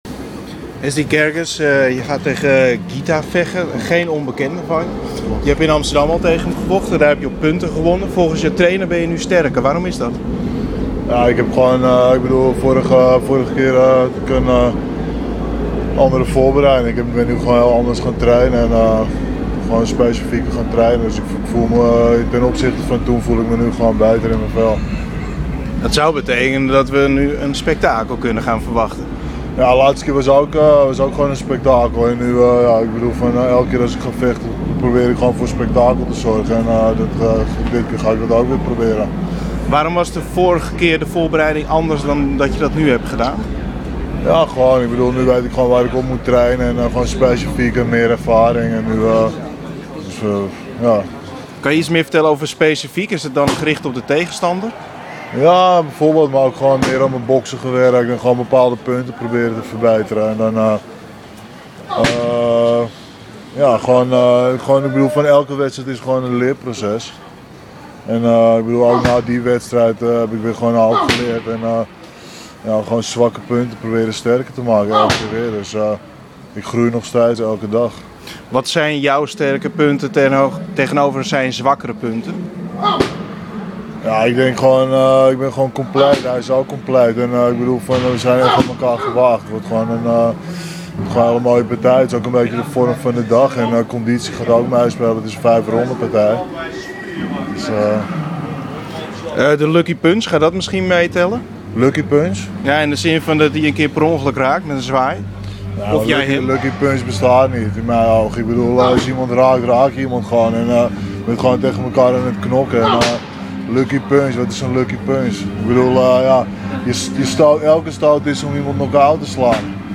Interview met: